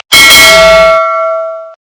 Loud Don Pollo Bell